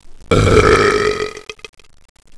burp.wav